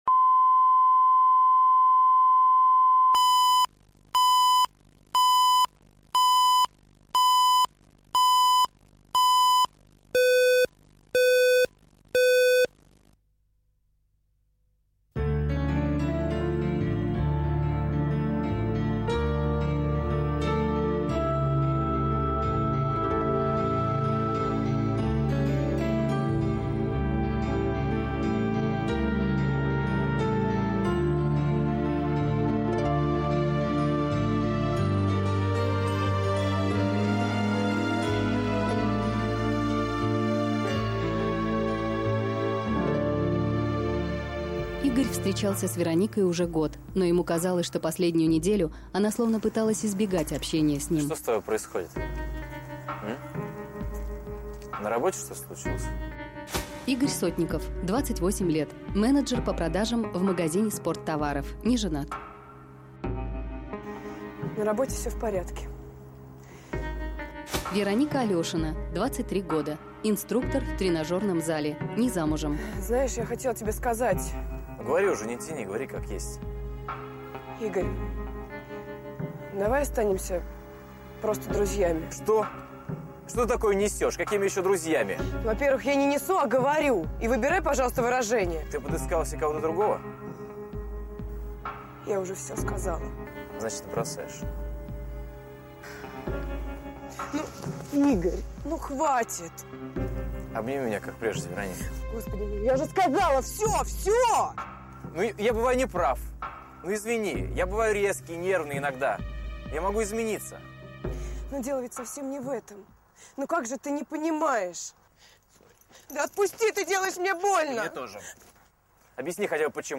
Аудиокнига Останемся друзьями